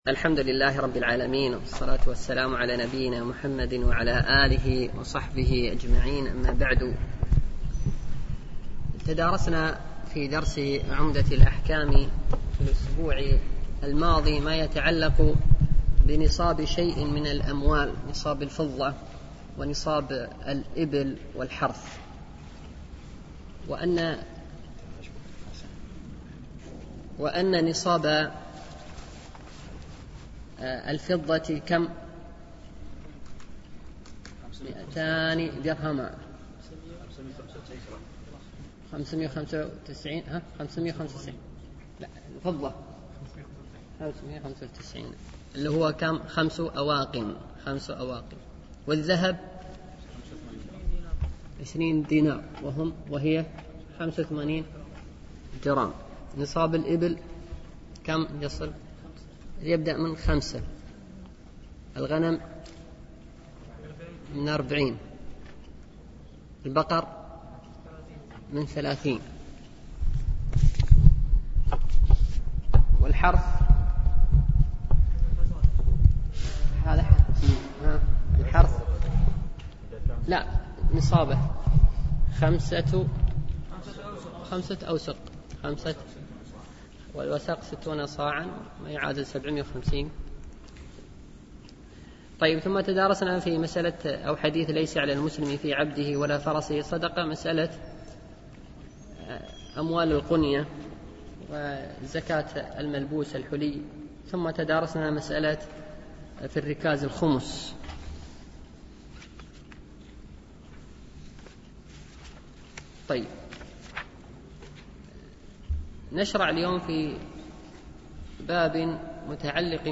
شرح عمدة الأحكام ـ الدرس الثامن عشر